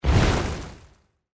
bonus_bomb.mp3